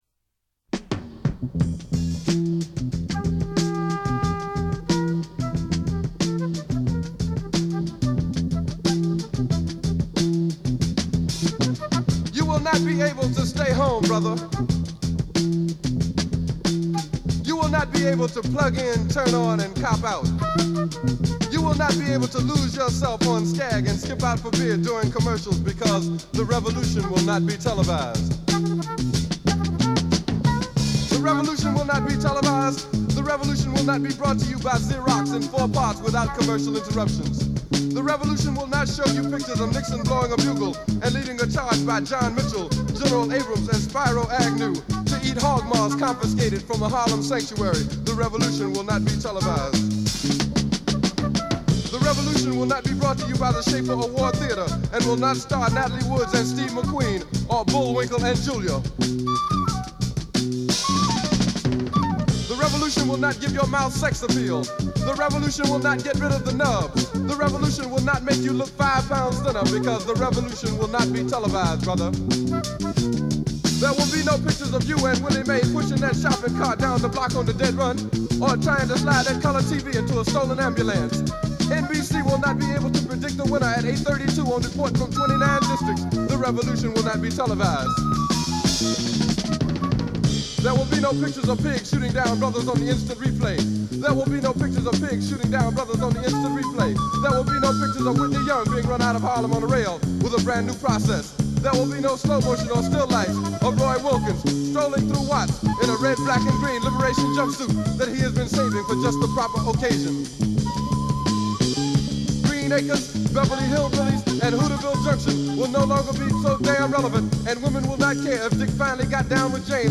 דיבור כמעט ראפ